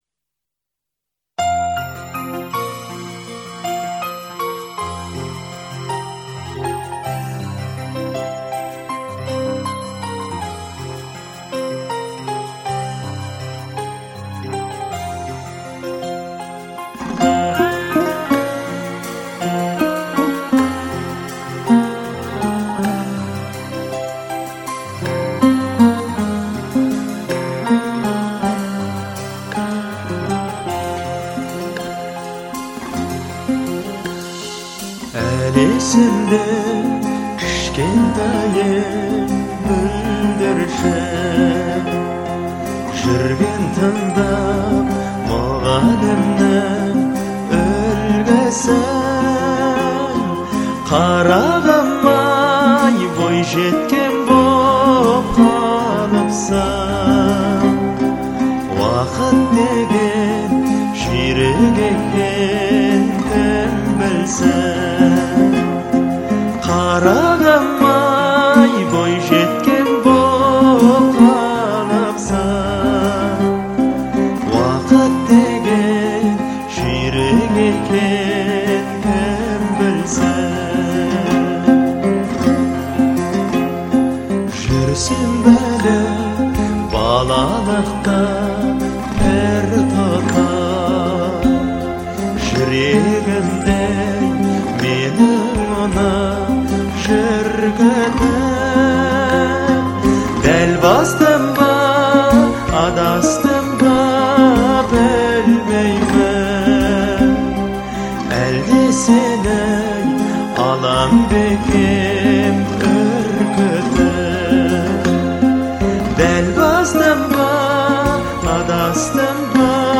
это яркий пример казахской поп-музыки
Особенностью исполнения является выразительный вокал